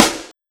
SNARE127.wav